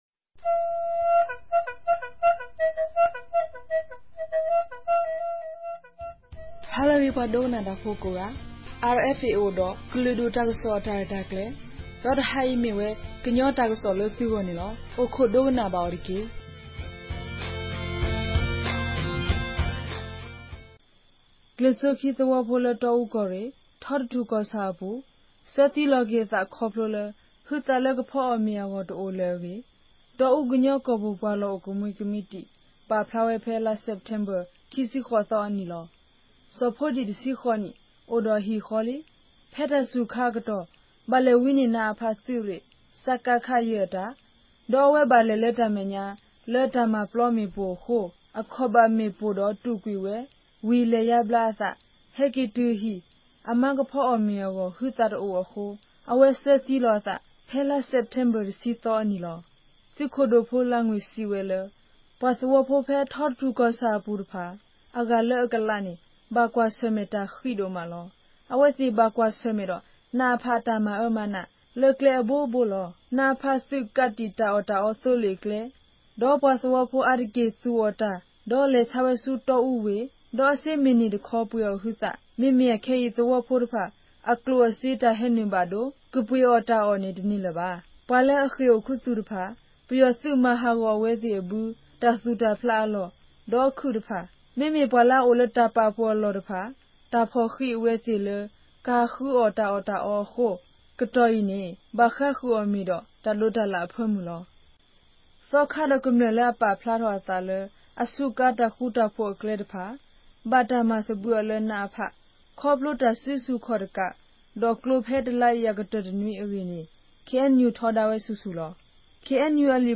ကရင်ဘာသာ အသံလြင့်အစီအစဉ်မဵား